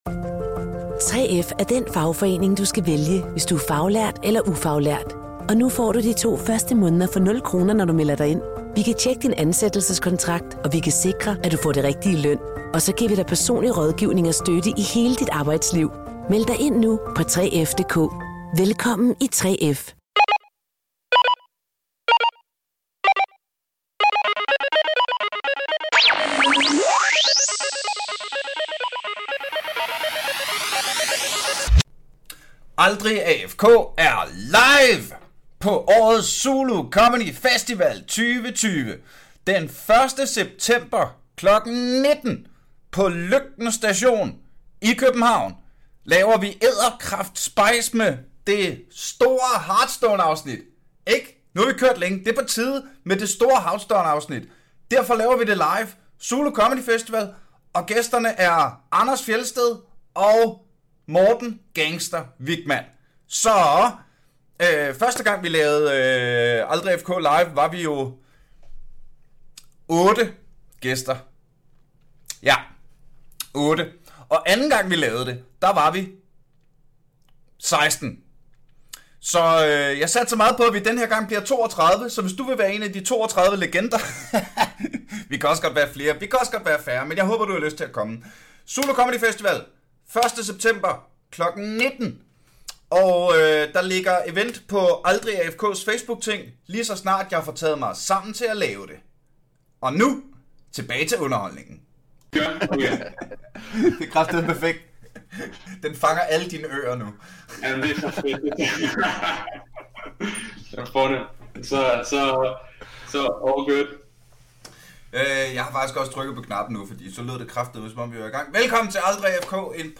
Svingende Lydkvalitet